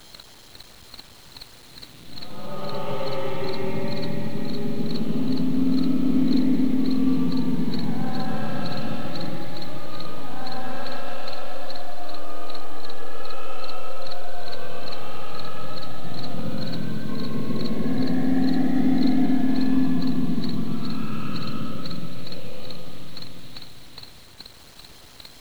.aiff (364 ko), qui vous permet d'entendre gémir des morts-vivants. (je dirais que c'est tiré de Doom)
moan3.aiff